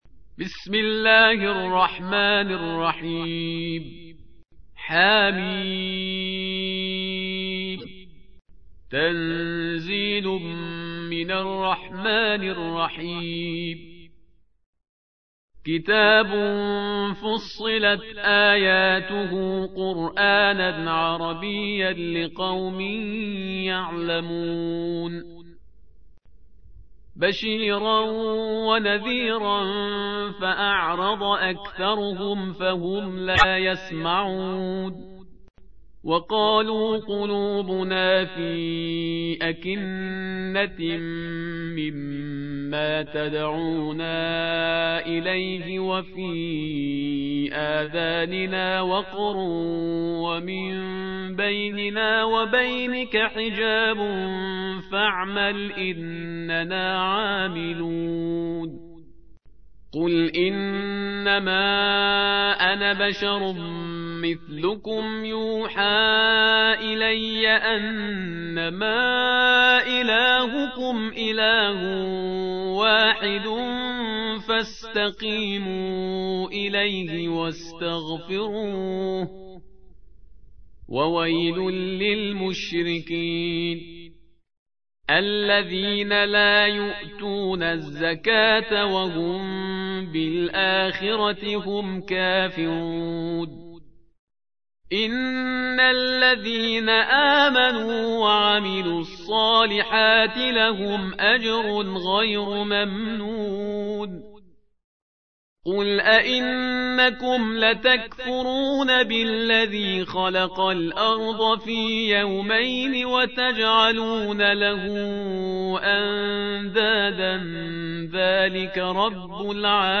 تحميل : 41. سورة فصلت / القارئ شهريار برهيزكار / القرآن الكريم / موقع يا حسين